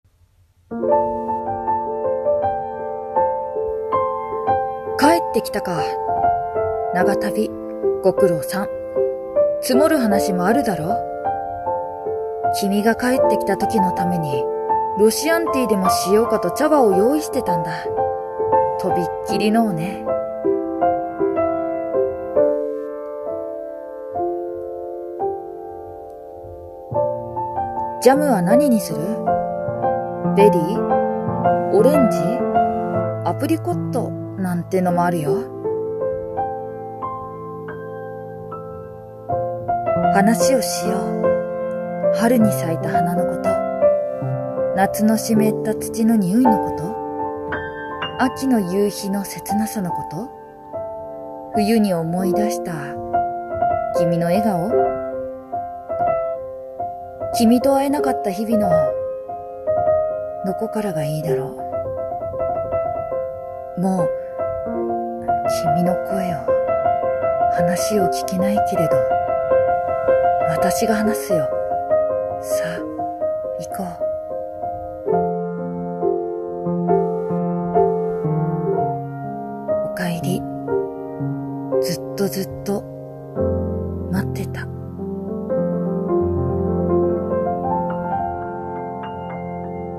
朗読【おかえり】